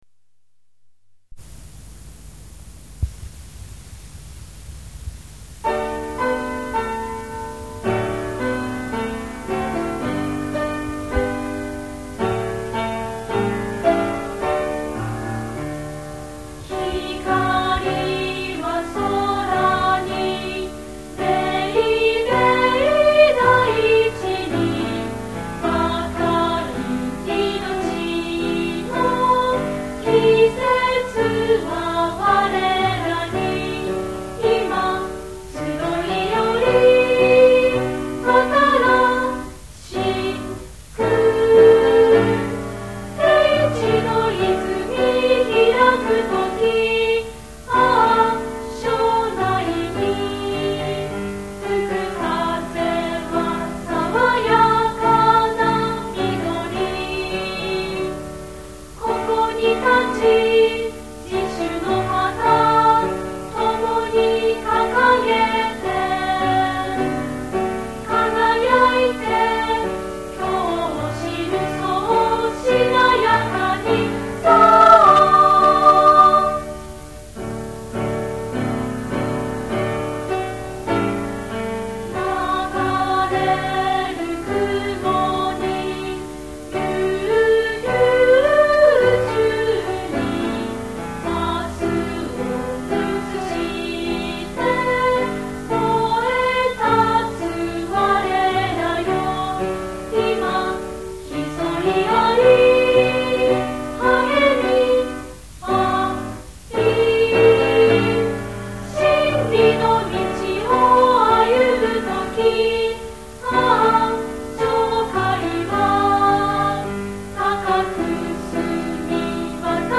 校歌
作詞／こわせ・たまみ　　作曲／中田喜直